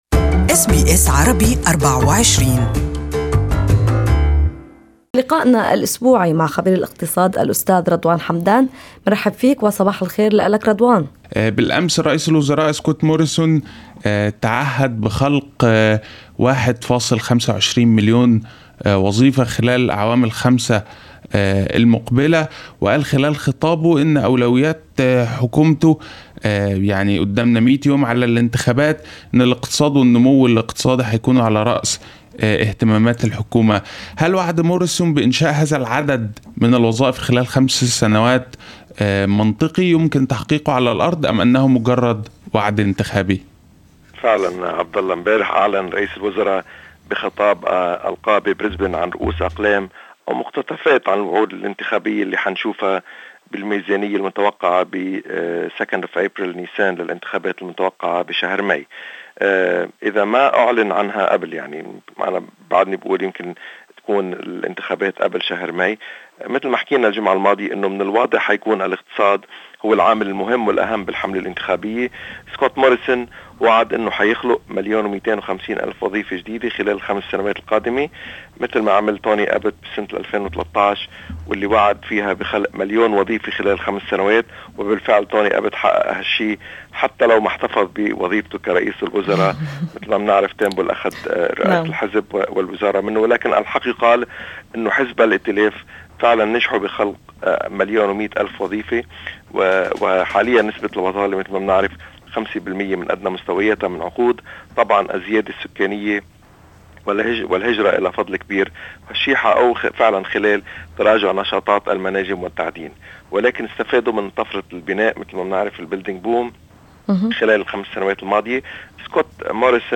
Listen to an interview with economic analyst